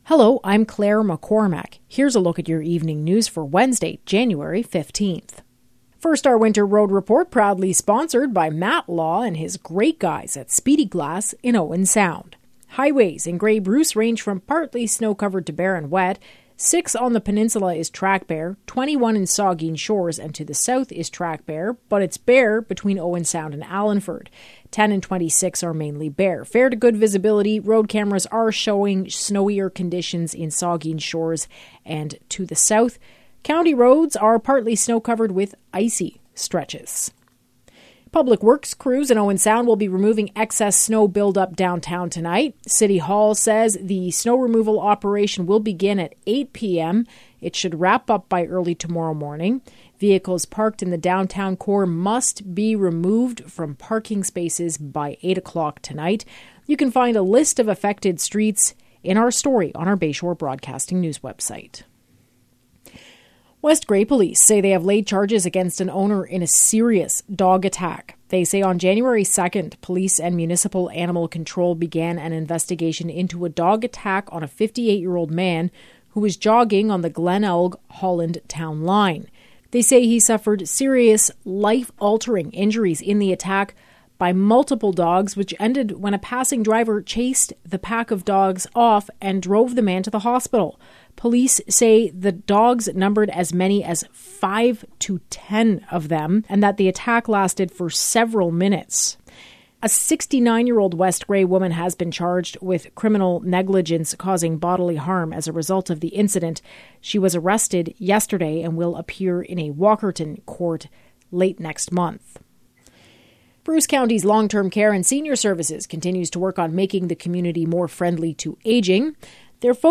Evening News – Wednesday, January 15